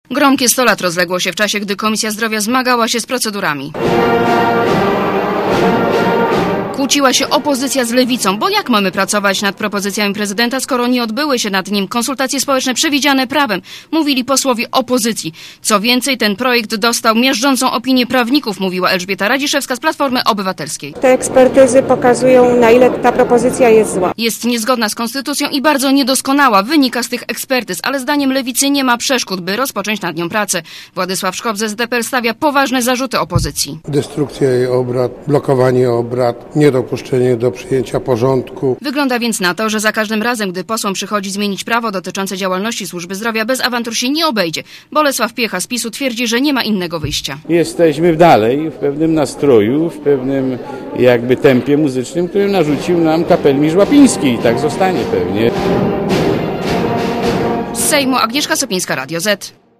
(RadioZet) Kłótnia w tle muzyki straży pożarnej, czyli ciąg dalszy obrad komisji zdrowia. Posłowie przez godzinę spierali się o to, nad jakimi projektami mają pracować i w jakim trybie.